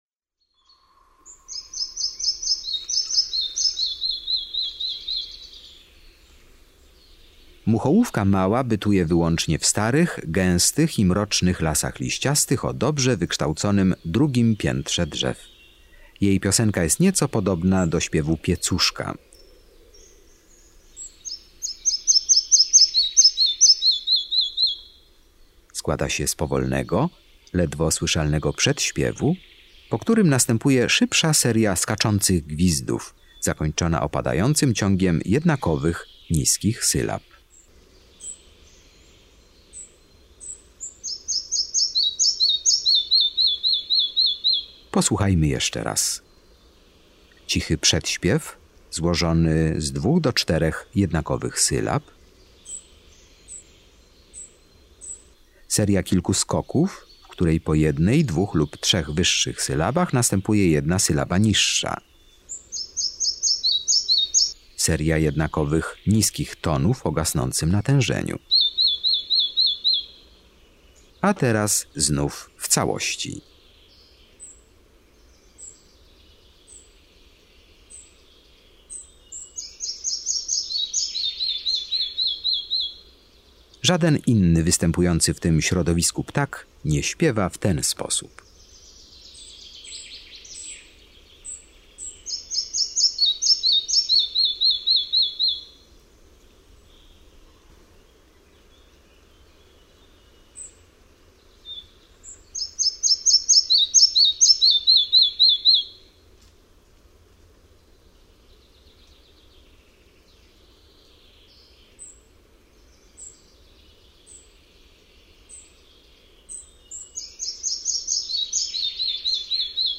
21 MUCHOŁÓWKA MAŁA.mp3